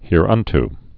(hîr-ŭnt)